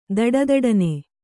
♪ daḍadaḍane